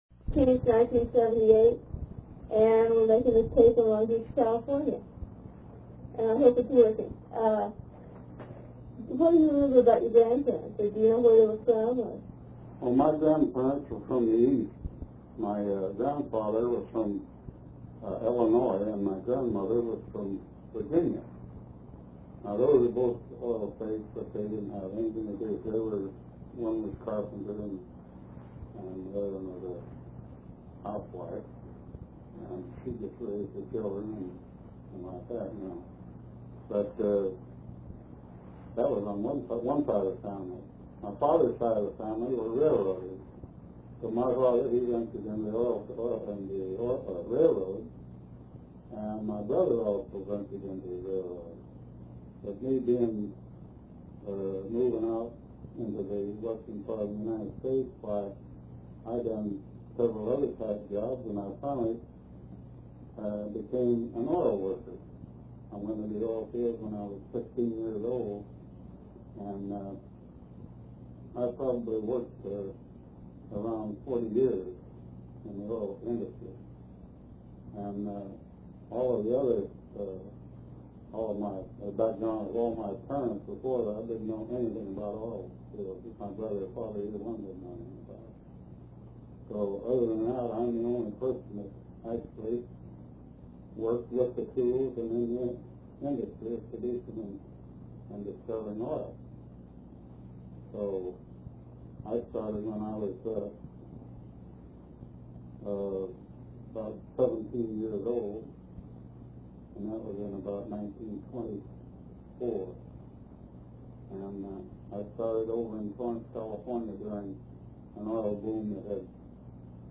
Although he was retired, he still spoke in a strong voice and described his work in colorful language.